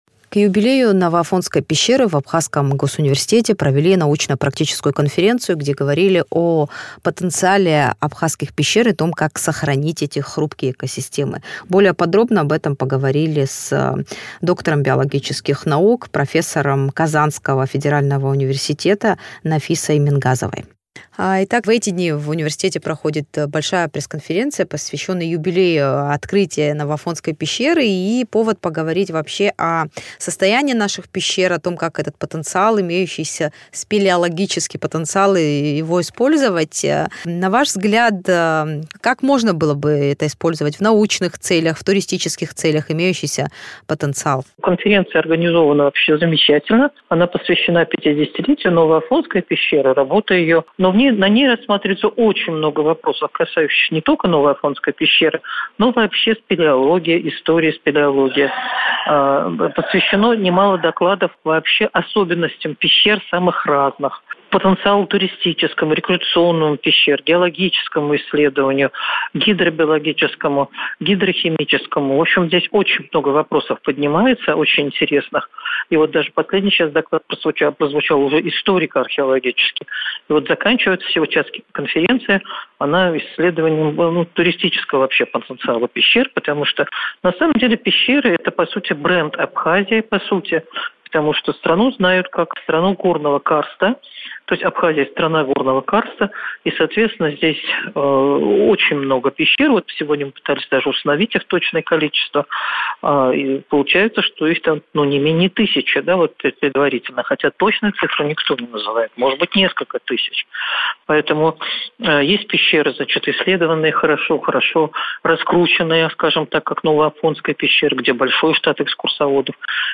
Пещеры, как бренд. Об уникальности карста Абхазии в интервью с профессором